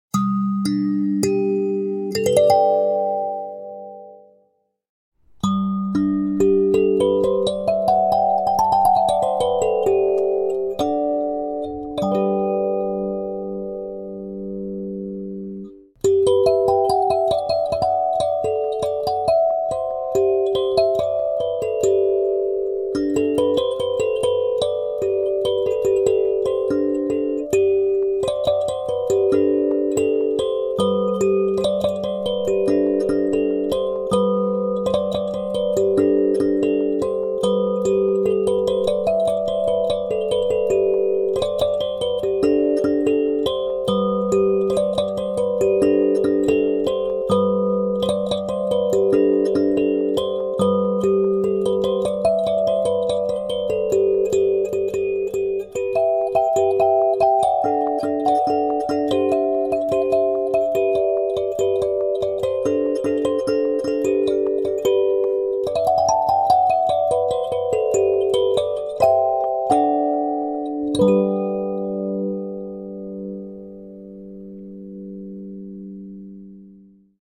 Sansula Basic Melody • Sol Majeur
La sansula « Melody » est un instrument d’exception offrant un son encore doux et vibrant, amplifié par la membrane qui lui confère une résonance unique.
Elle permet de créer des harmonies apaisantes, idéales pour l’exploration musicale et les pratiques de sonothérapie.
• 11 lamelles accordées pour une harmonie fluide et intuitive
• Membrane amplificatrice, offrant un son profond et vibratoire
Sansula-Basic-Melody-Sol-majeur.mp3